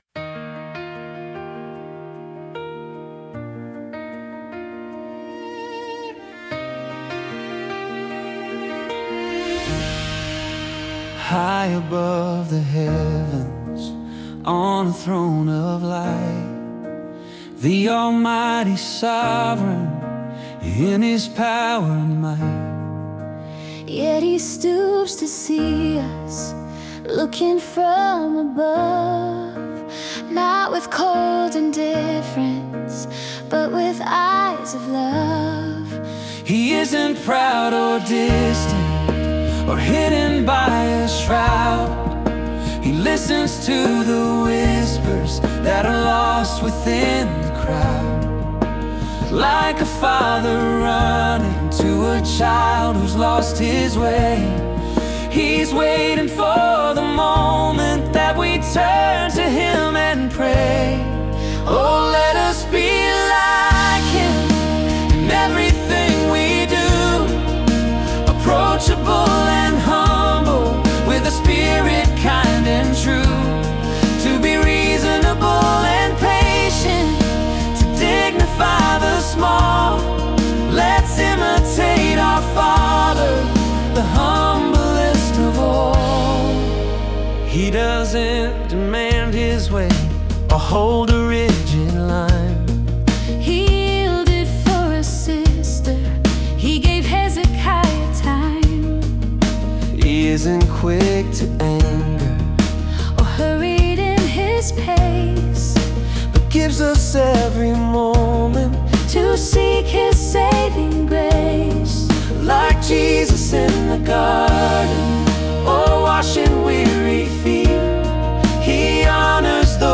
My AI Created Music